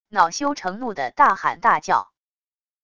恼羞成怒的大喊大叫wav音频